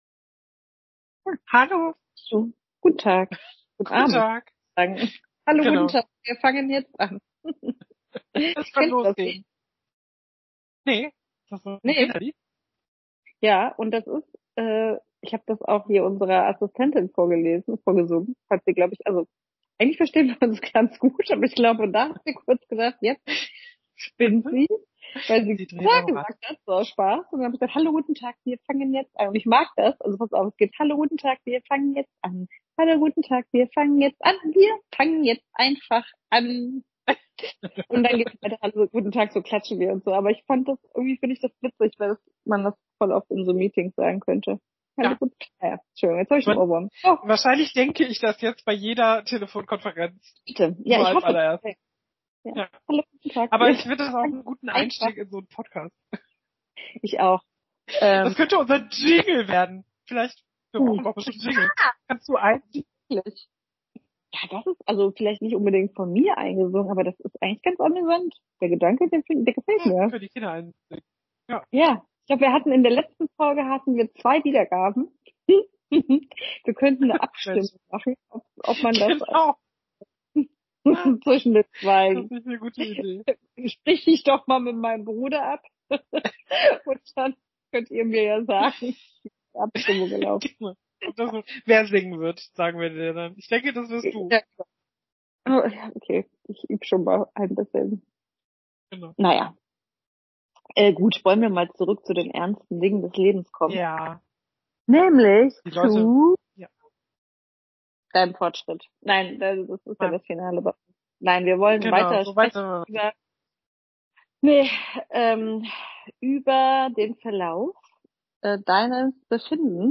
September 2024 Sechs Spritzen später und die Dosis steigt – aber auch die Nebenwirkungen? In dieser Folge sprechen die beiden Freundinnen ehrlich über das erste Tief